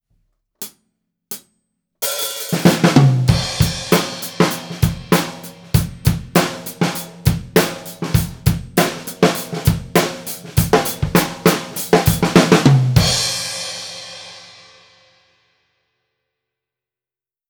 すべて、EQはしていません。
タムの真上に立てています。
全体がバランス良く録れています。